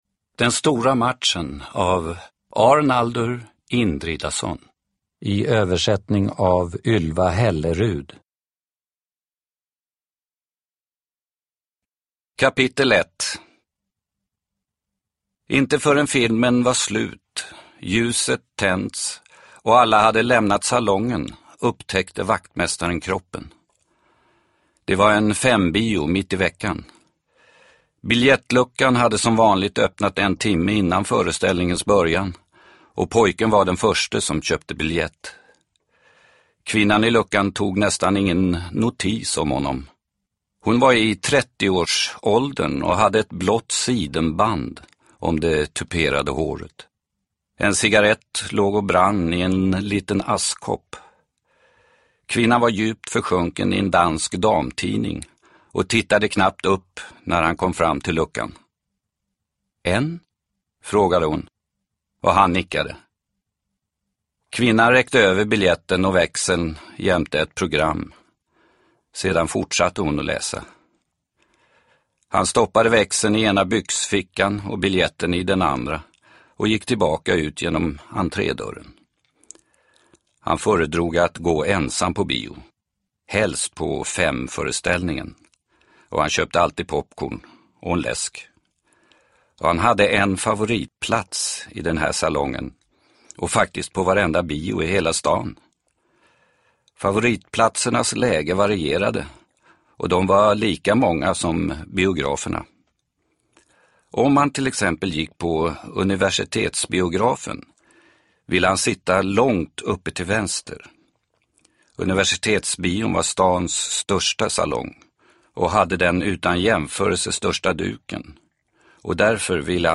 Den stora matchen – Ljudbok – Laddas ner